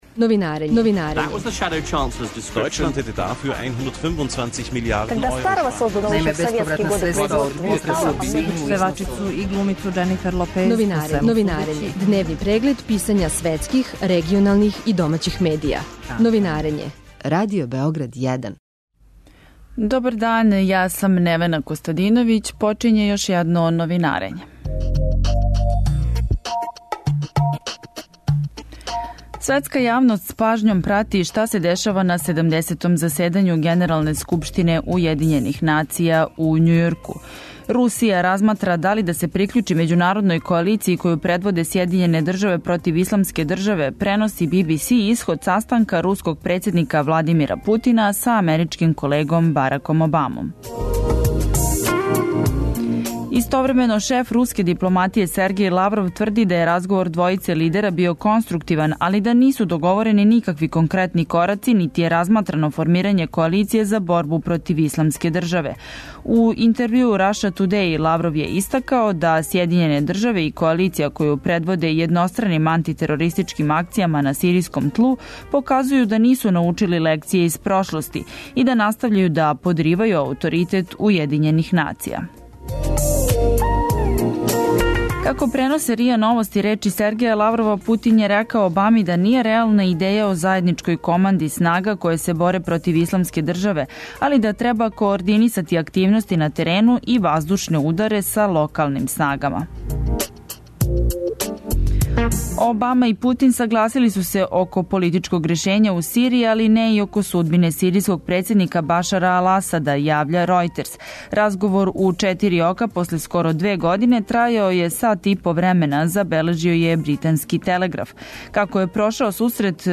Радио Београд 1